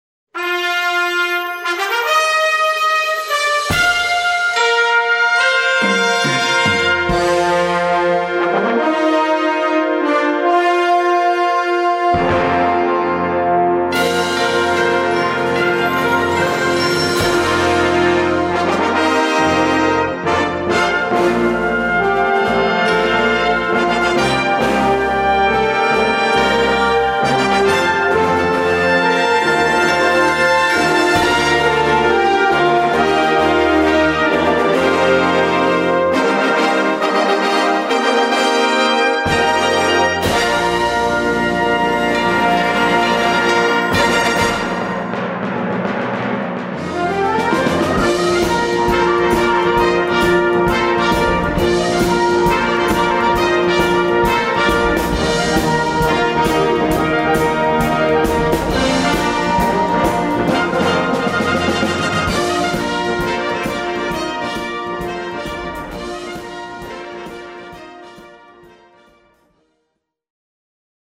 Gattung: Konzertmusik
Besetzung: Blasorchester